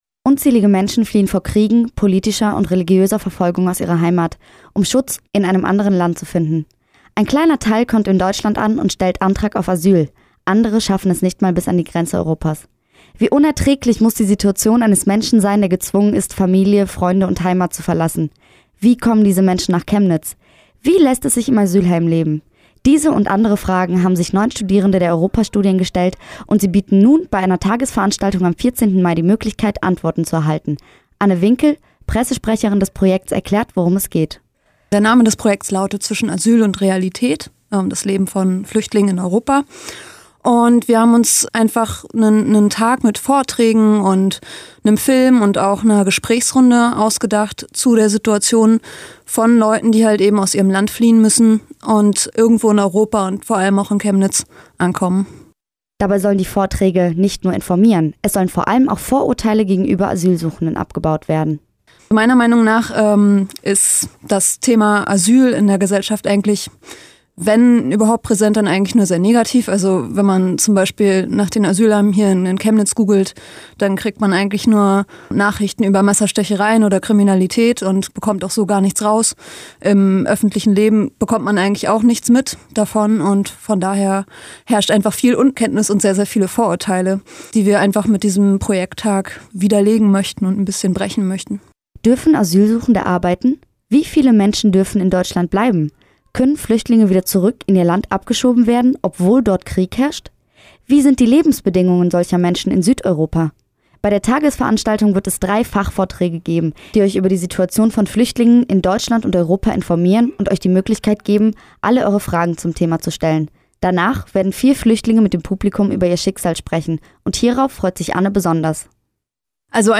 Kurzreport auf Radio UniCC (mp3, ca. 3,5 mB)